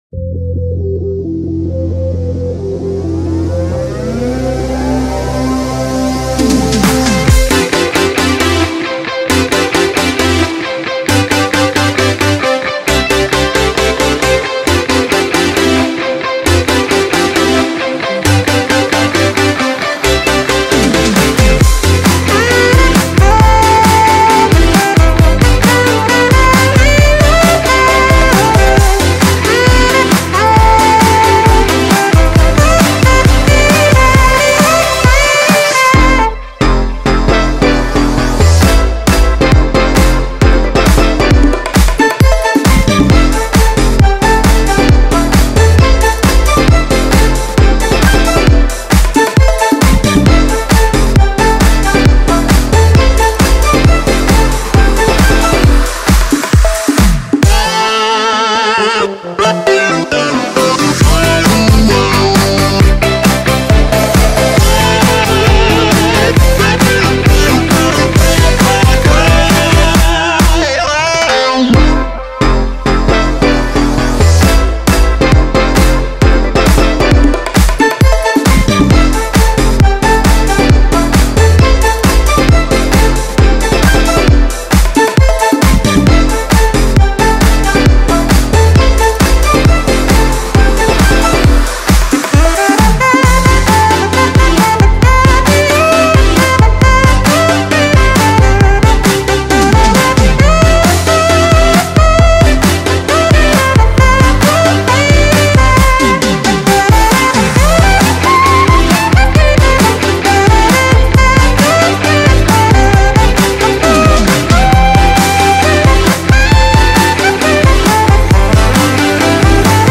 BPM134-135
Comments[TROPICAL SYNTH HOUSE]